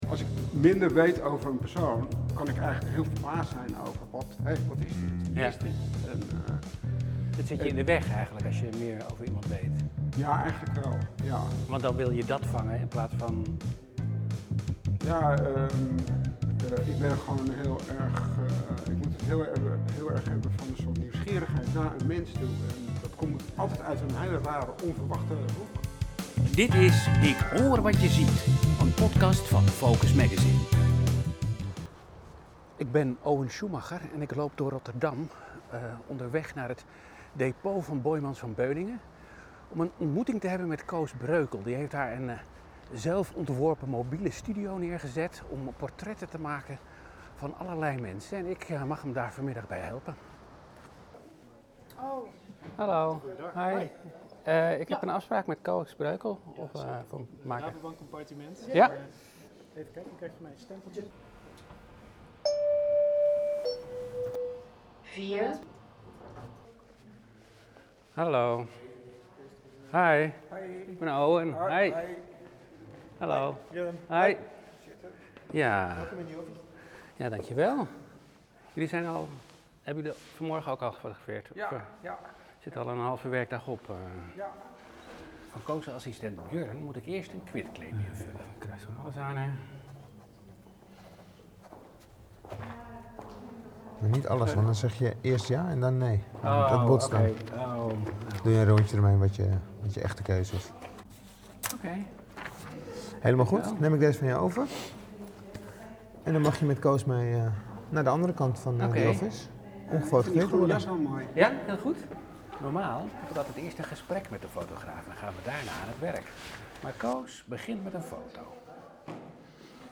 Na een korte kennismaking wordt het gesprek in de studio of onderweg voortgezet.